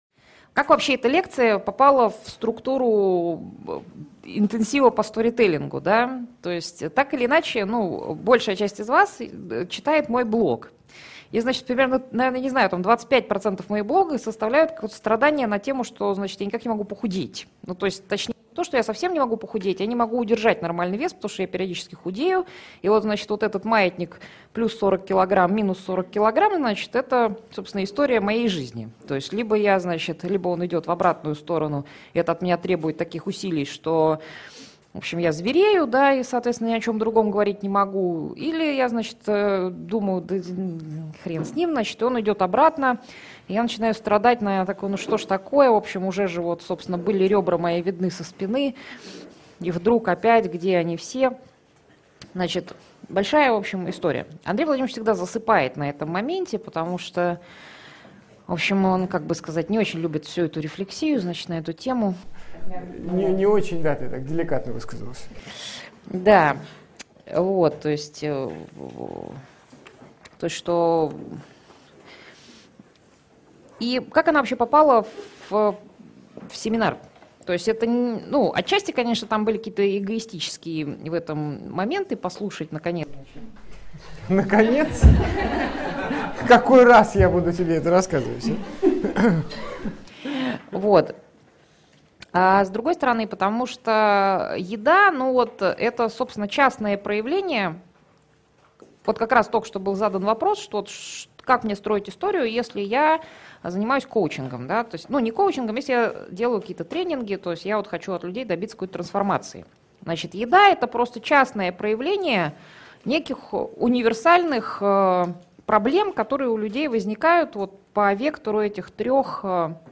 Аудиокнига Роман с едой | Библиотека аудиокниг
Aудиокнига Роман с едой Автор Андрей Курпатов Читает аудиокнигу Андрей Курпатов.